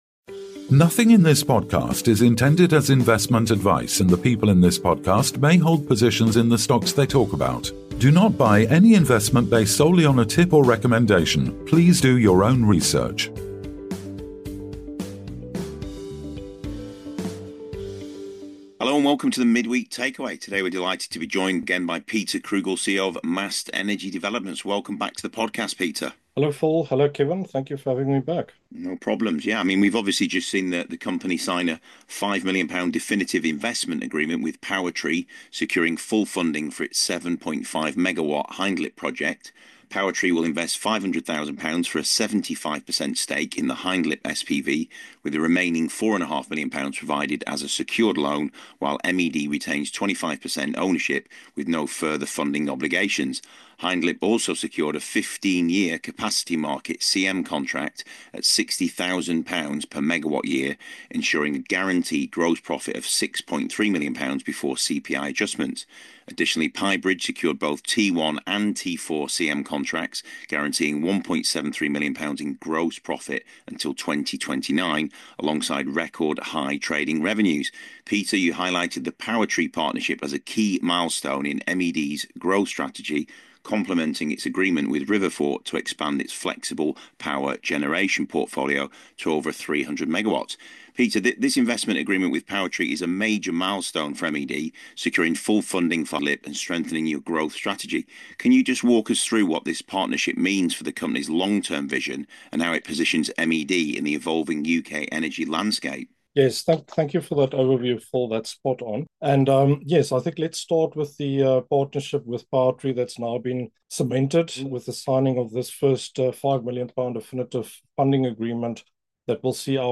aiming to expand its flexible power generation portfolio to over 300 MW. For a deeper understanding of MED's recent developments and future plans, tune in to this insightful conversation.